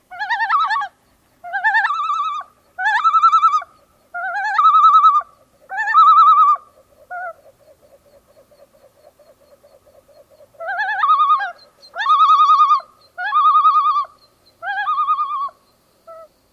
白嘴潜鸟恐怖叫声 黄嘴潜鸟鸣叫声